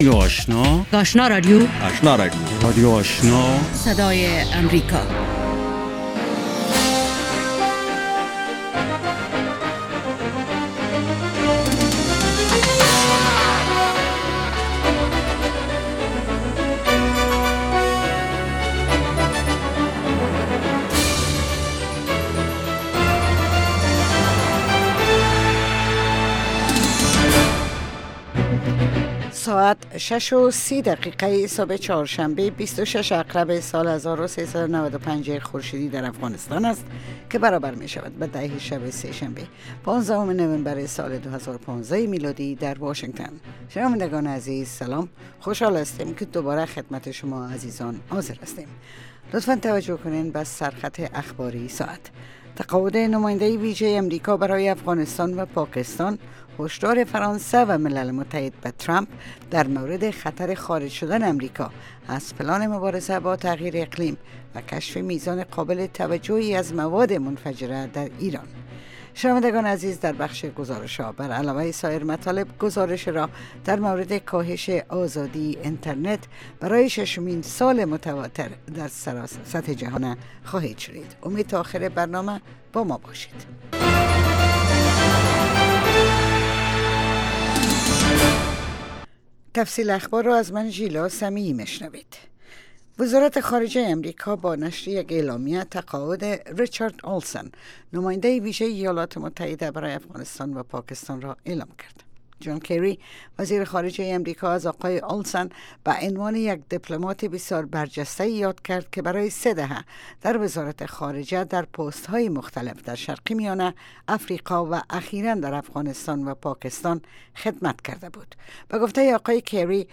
دومین برنامه خبری صبح